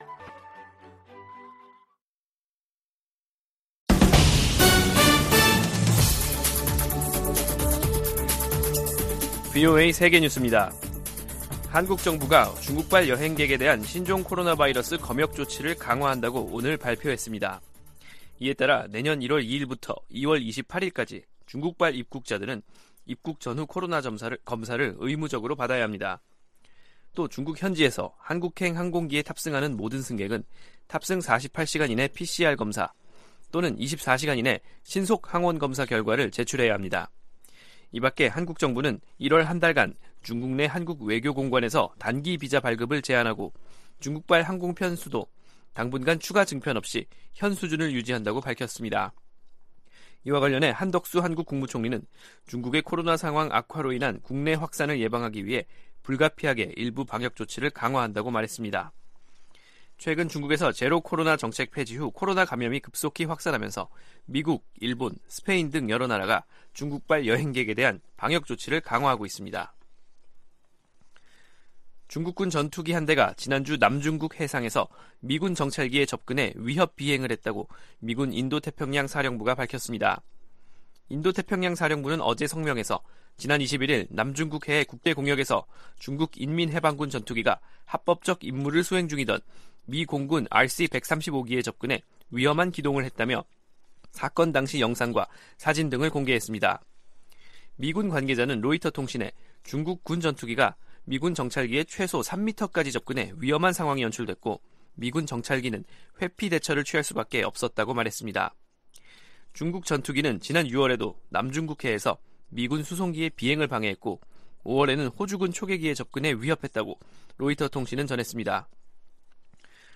VOA 한국어 간판 뉴스 프로그램 '뉴스 투데이', 2022년 12월 30일 2부 방송입니다. 북한의 무인기 무단 침입으로 9.19 남북 군사합의가 유명무실화하면서 한국 정부는 공세적 대응을 예고하고 있습니다. 미국 국무부가 미북 이산가족 상봉을 위한 노력을 계속하고 있다는 입장을 확인했습니다.